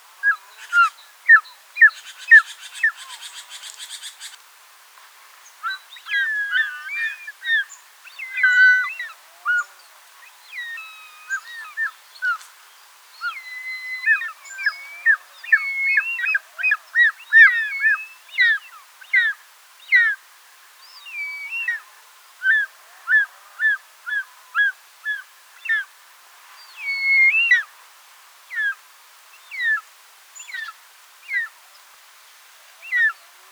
Schlangenadler – Wikipedia
call3.mp3